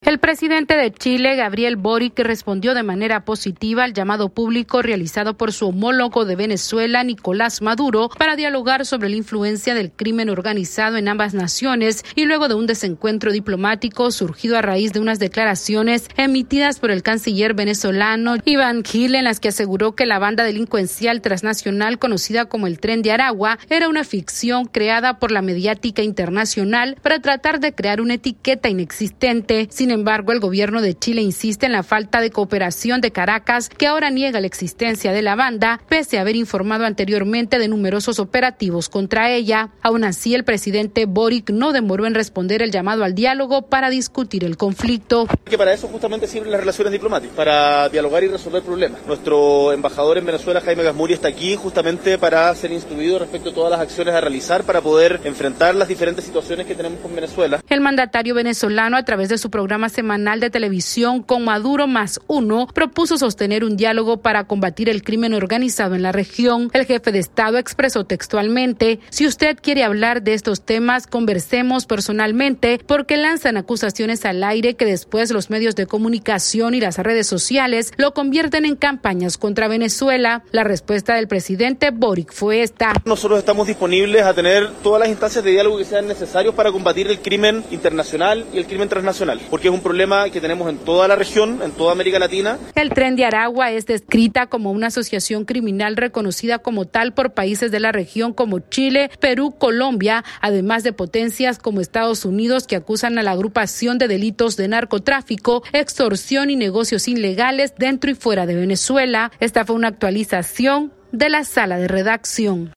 Esta es una actualización de nuestra Sala de Redacción...